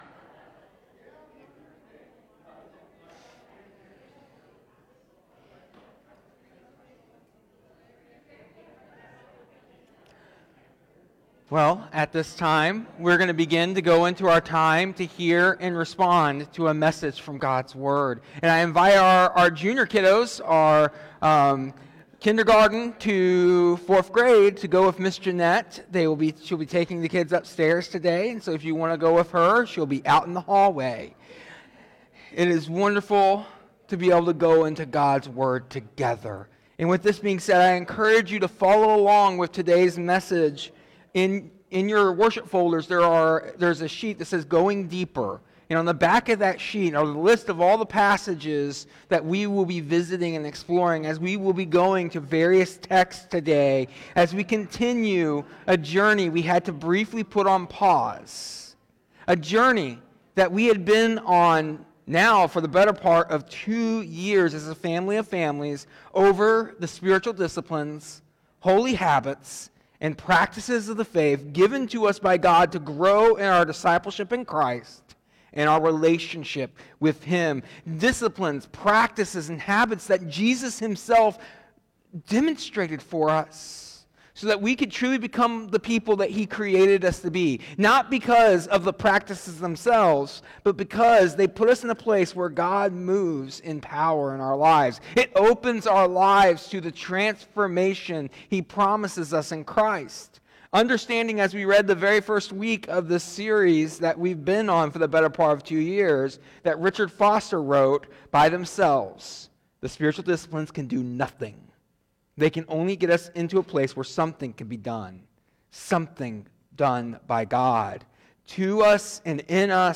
In this sermon, we […]